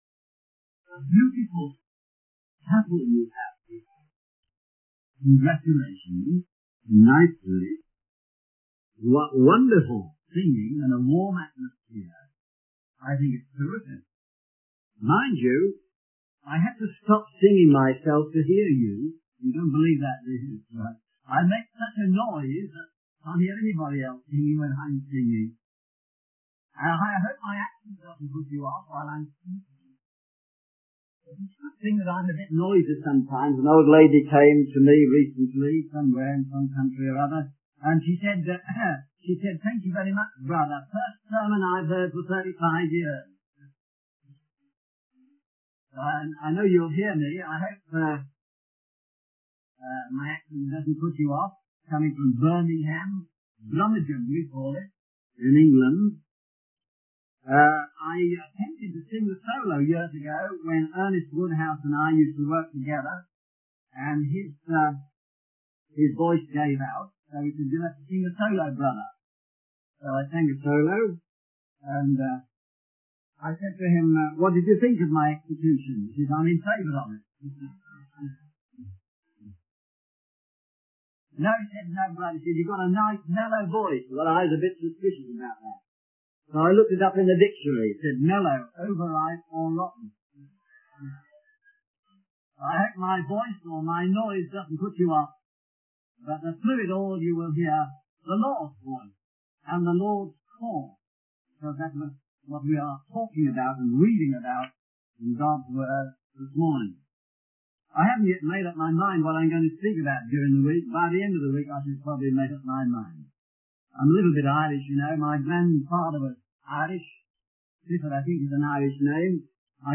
In this sermon, the speaker emphasizes the importance of moving forward in God's victory and rest. He references the story of the Israelites entering the promised land and how they experienced the Lord's presence, guidance, and blessings as they moved forward.